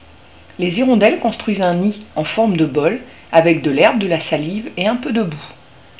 L'hirondelle des fenêtres
Le cri de l'hirondelle des fenêtres